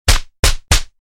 连续耳光.mp3